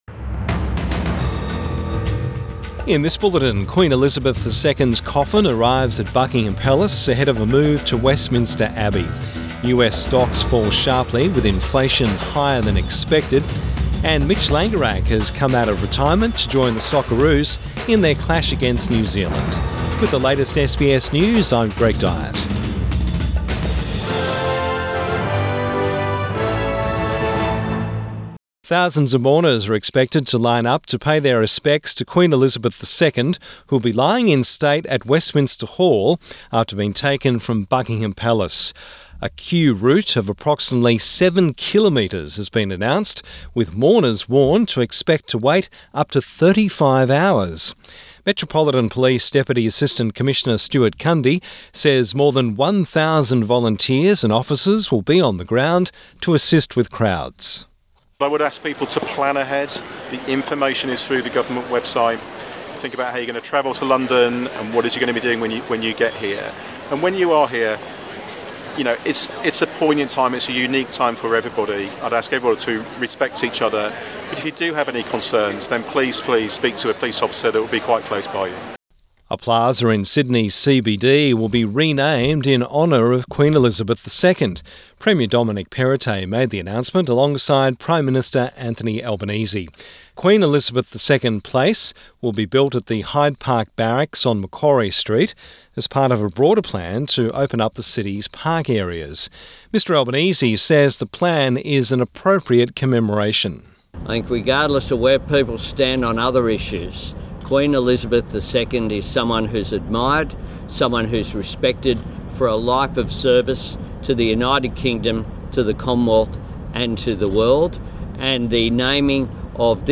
Midday bulletin 14 September 2022